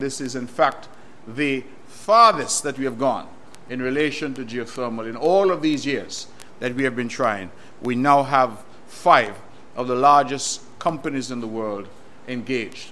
That was Premier, the Hon. Mark Brantley, during the July 3rd Sitting of the Nevis Island Assembly. On June 30th, 2025, the bids for the Geothermal Project was opened for the drilling of three production wells and two reinjection wells, at the Hamilton site which are intended for the development of a 30-megawatt (MW) geothermal power plant.